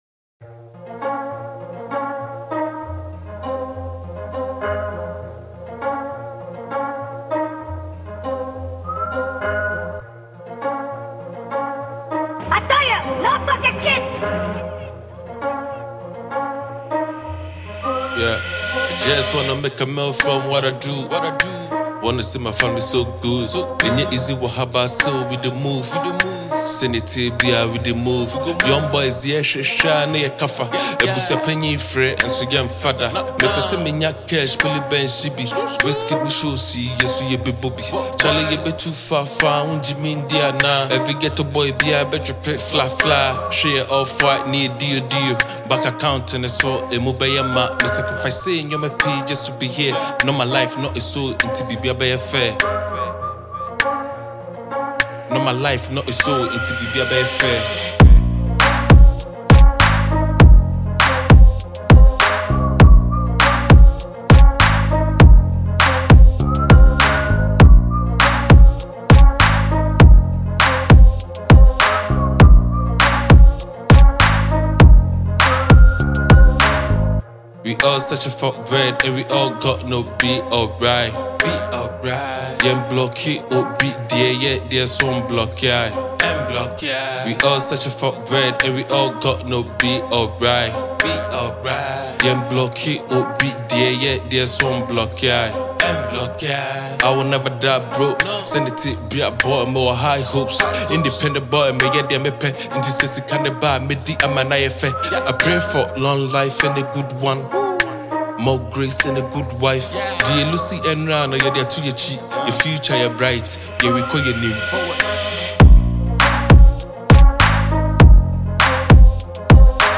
drill tune